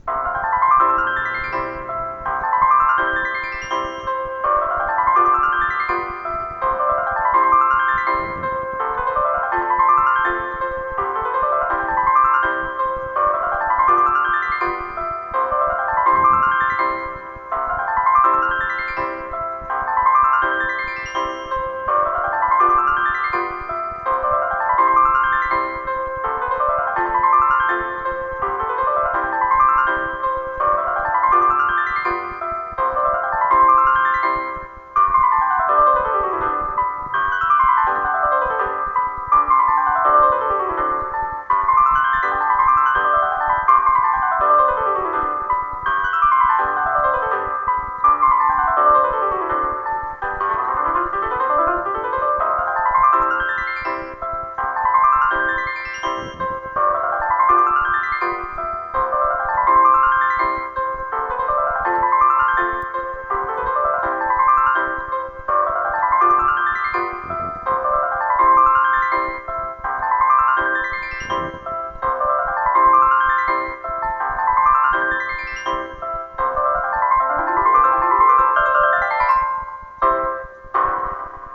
Peça per a piano
peça-per-a-piano.mp3